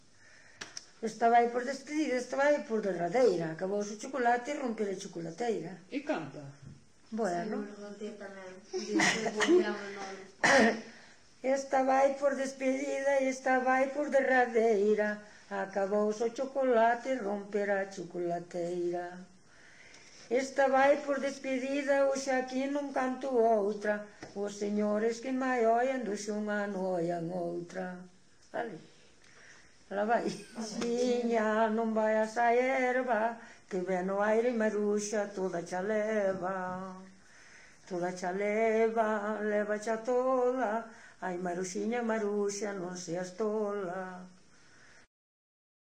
Coplas
Palabras chave: coplas despedida
Soporte orixinal: Casete
Instrumentación: Voz
Instrumentos: Voz feminina